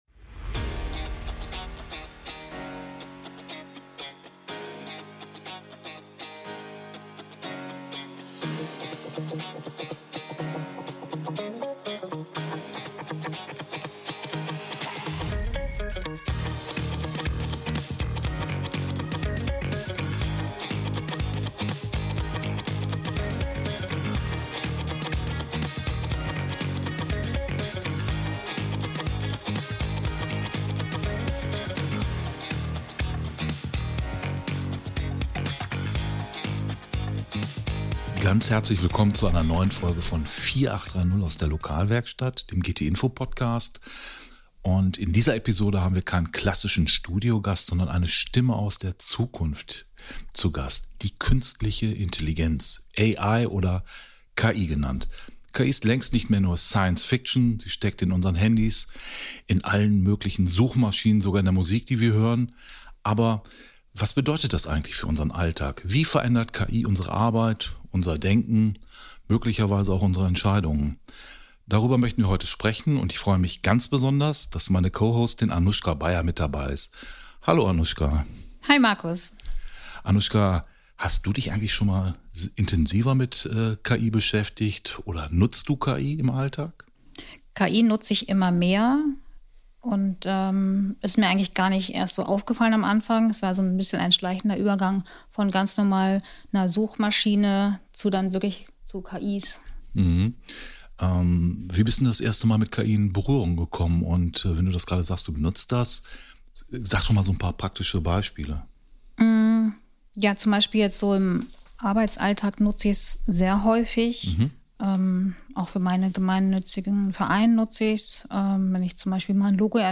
Sie unterhalten sich mit ihr.